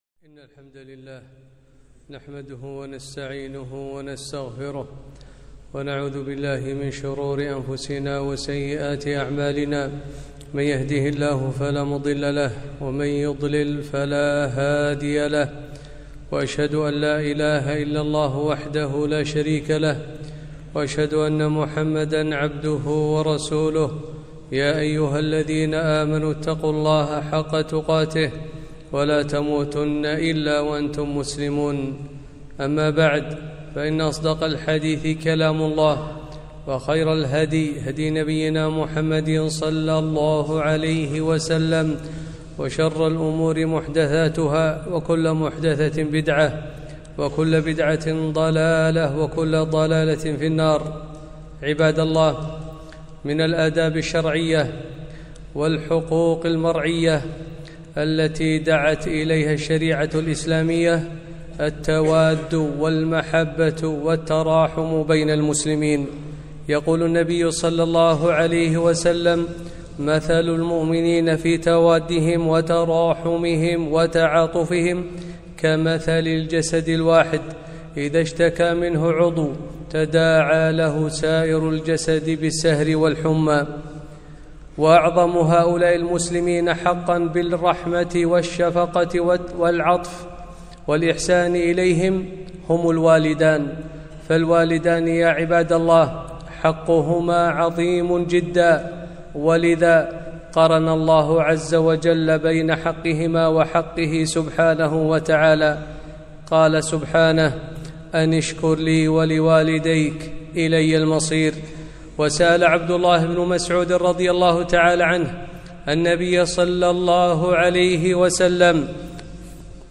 خطبة - التواد والرحمة بين المسلمين - دروس الكويت